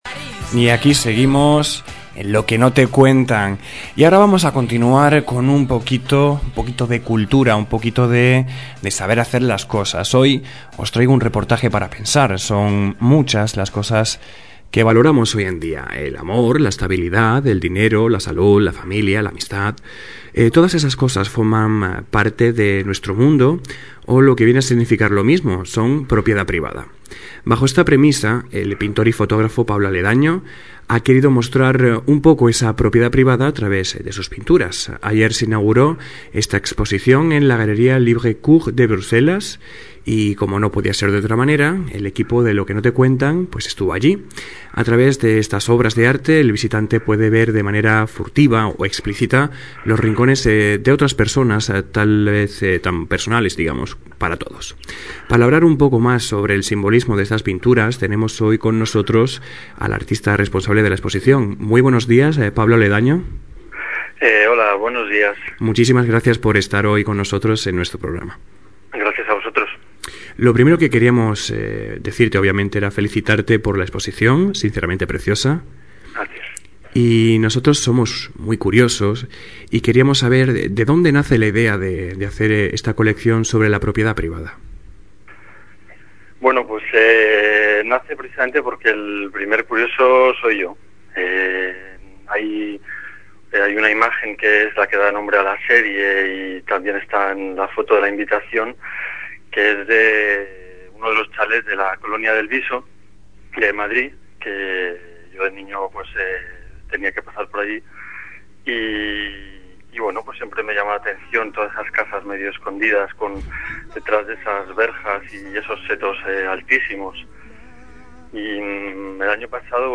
RADIO ALMA, 14 de febrero de 2009 INTERVIEW